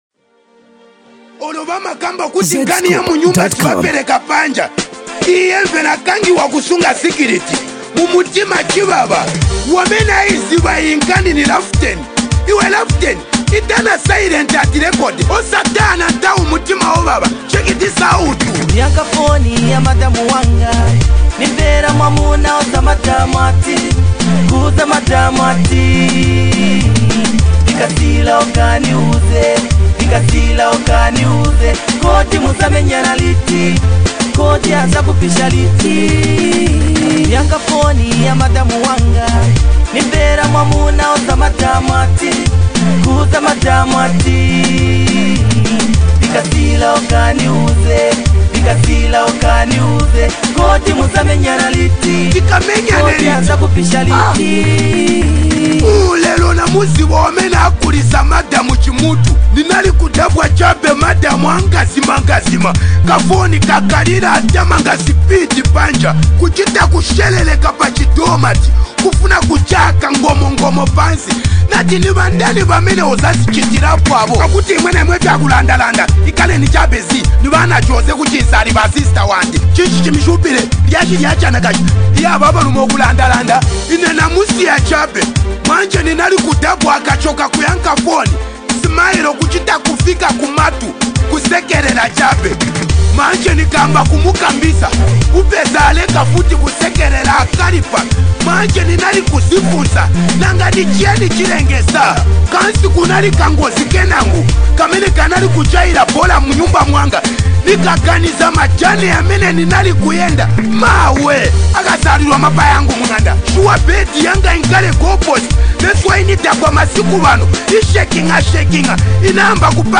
Old Zambian Music
soft voice rendering a beautiful voice